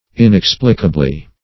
inexplicably - definition of inexplicably - synonyms, pronunciation, spelling from Free Dictionary Search Result for " inexplicably" : The Collaborative International Dictionary of English v.0.48: Inexplicably \In*ex"pli*ca*bly\, adv.